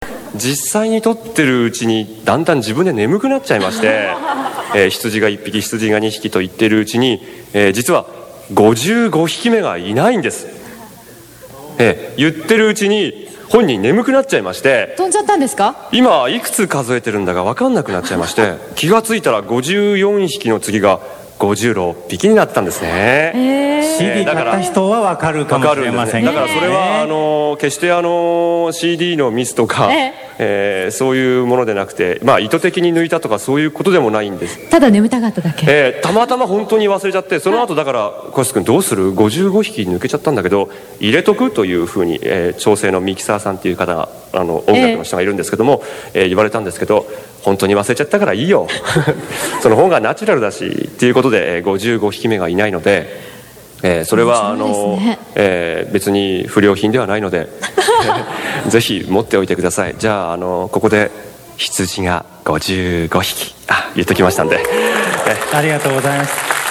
１９９６年１２月、南郷町のハートフルセンターで日南線にのってアニメトレインで公録をたのしもう！という面白い企画があった。ゲストは神谷 明さんと子安武人さん、ふしぎ遊戯の星宿、勇者指令ダクオンで活躍の子安武人さんにインタビューした。